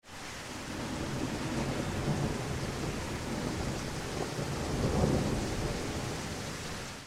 LLUVIA
LLUVIA - Tono movil - EFECTOS DE SONIDO
Tonos gratis para tu telefono – NUEVOS EFECTOS DE SONIDO DE AMBIENTE de LLUVIA
lluvia.mp3